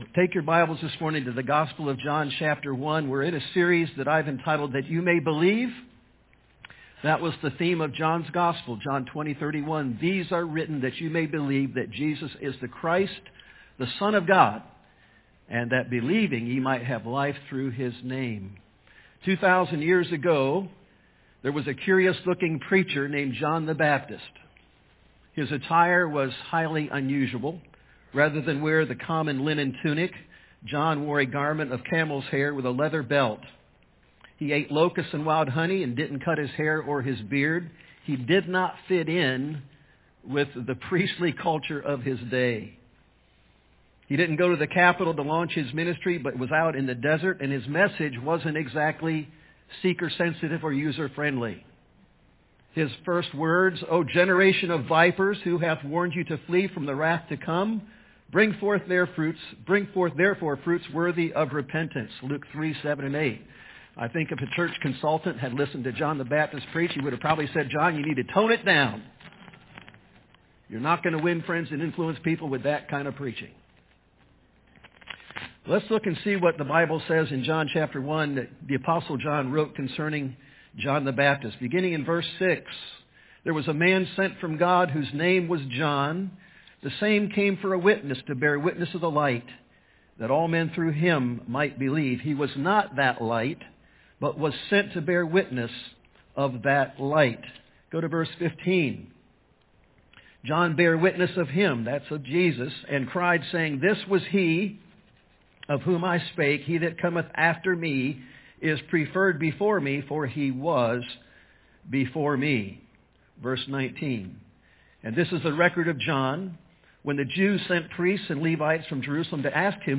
That You May Believe Passage: John 1:6-8, 15, 19-34 Service Type: AM